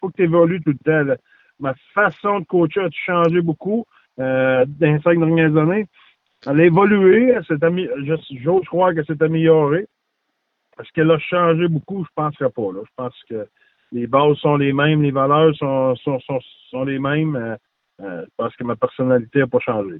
En entrevue, le coach du Mammoth de l’Utah est revenu sur les quelques changements qu’il a apportés au fil des années.